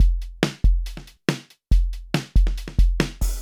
hab mir vorhin auch das voxengo-teil geholt und es als erstes mit einem drum loop ausprobiert.
ohne tape
drums-no-tape.mp3